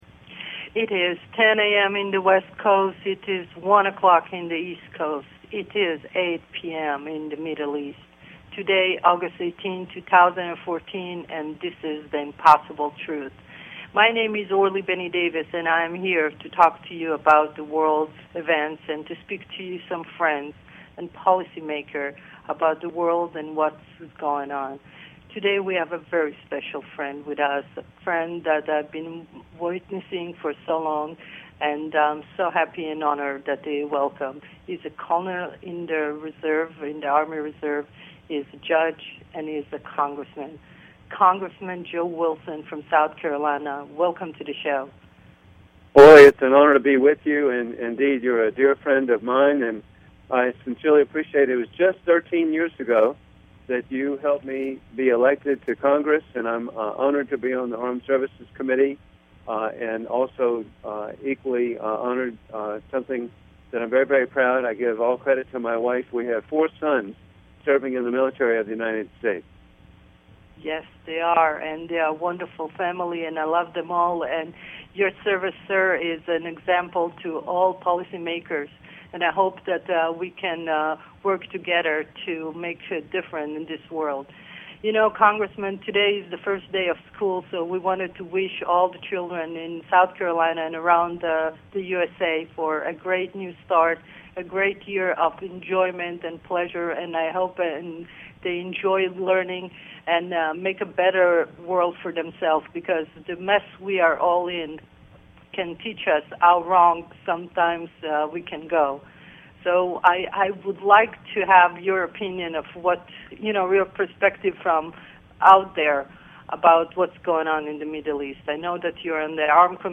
Guests, Congressman Joe Wilson and Judge and General Frank Simon